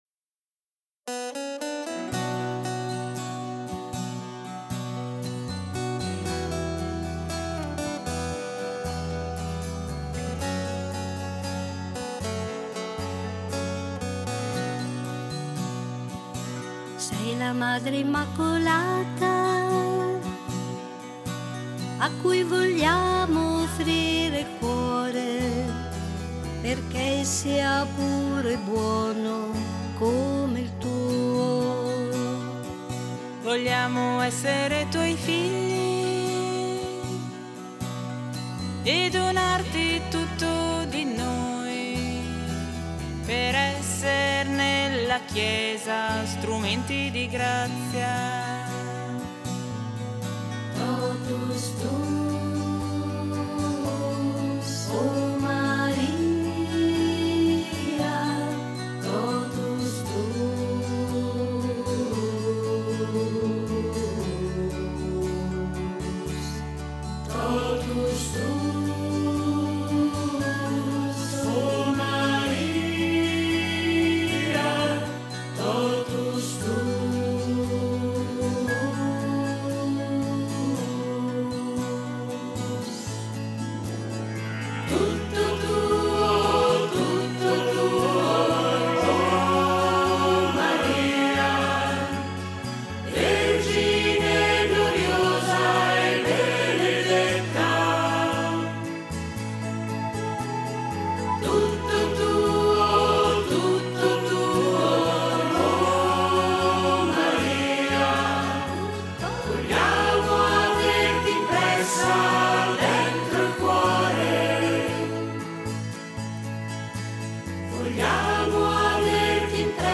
Coro
Esattamente per questi motivi, abbiamo registrato voci e musiche di alcuni di questi canti, trascrivendone gli spartiti, perché restino a disposizione di chi vorrà lodare e ringraziare il Signore e la Sua dolcissima Madre.